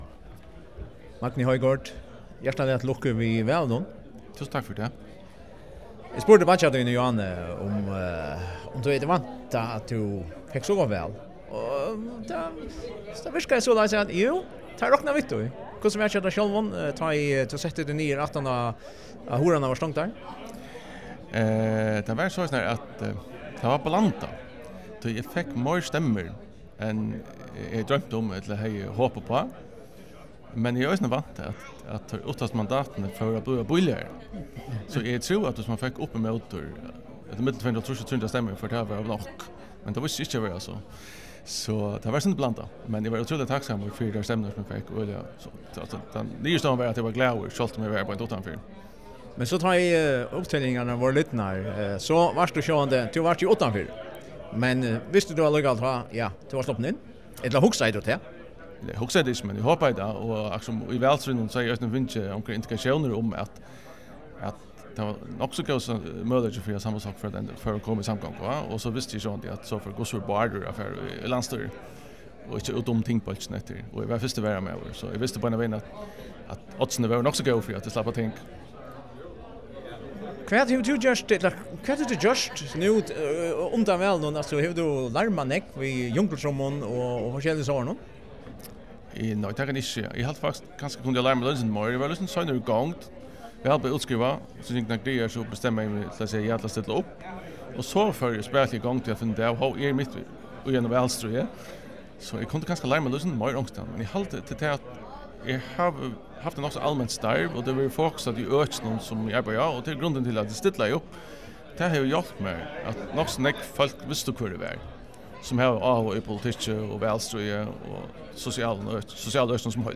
á móttøkuni úti í Tinganesi.